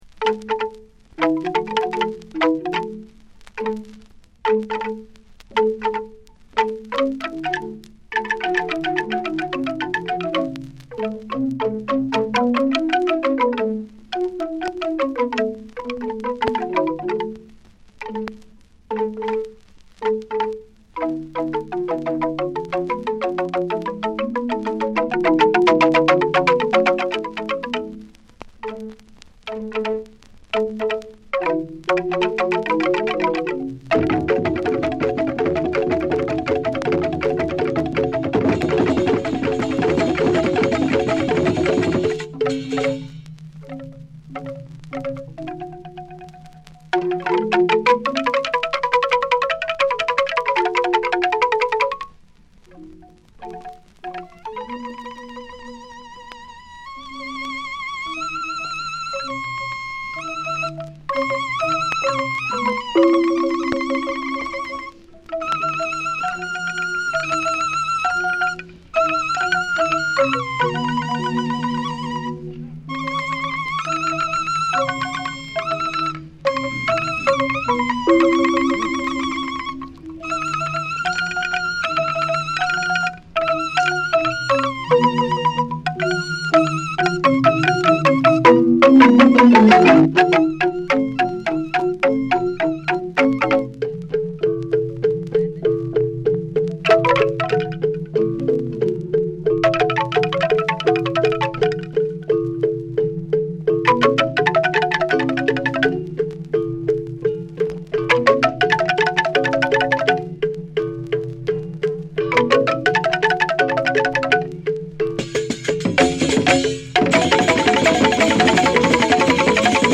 ガムランを中心とした全10曲。金属音や木琴の反復がミニマルに響く抑揚と熱量でトリップ感もある傑作です。